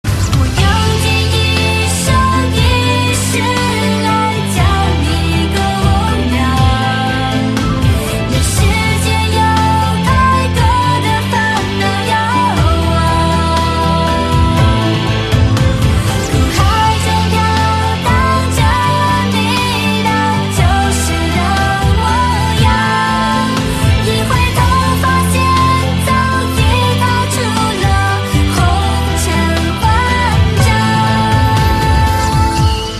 Thể loại nhạc chuông: Nhạc trung hoa